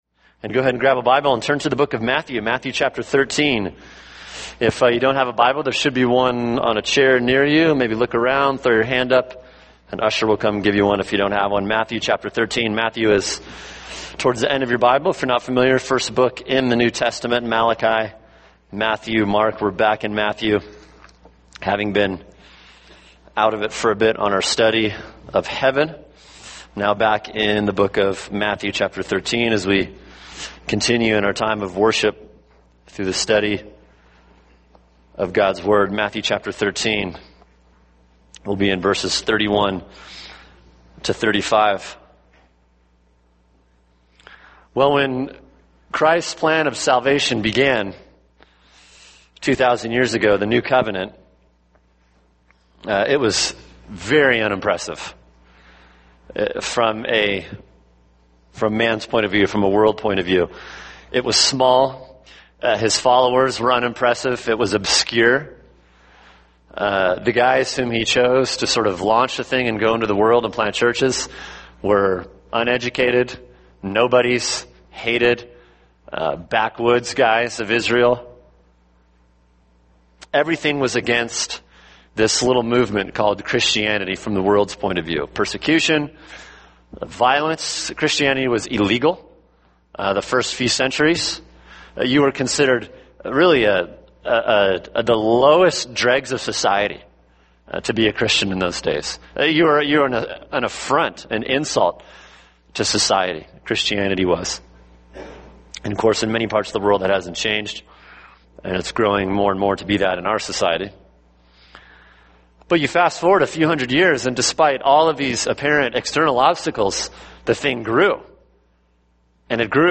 [sermon] Matthew 13:31-35 – The Growth of the Kingdom | Cornerstone Church - Jackson Hole